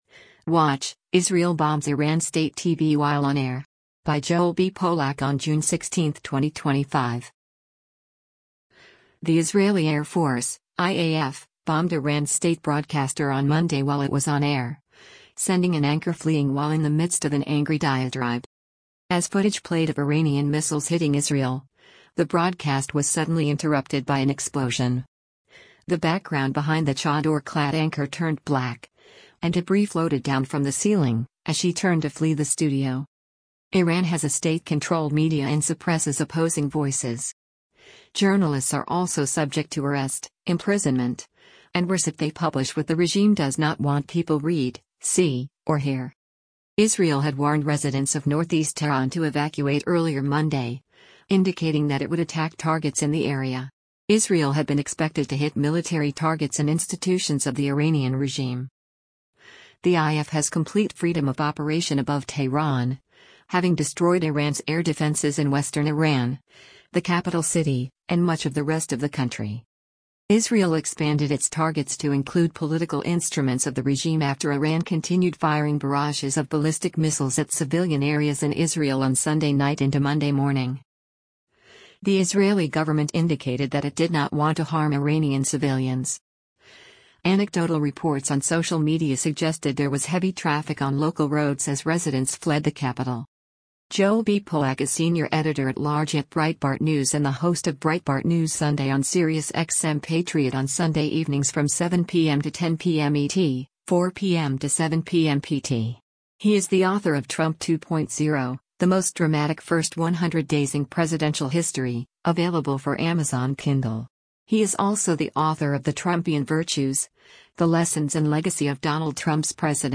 The Israeli Air Force (IAF) bombed Iran’s state broadcaster on Monday while it was on air, sending an anchor fleeing while in the midst of an angry diatribe.
As footage played of Iranian missiles hitting Israel, the broadcast was suddenly interrupted by an explosion.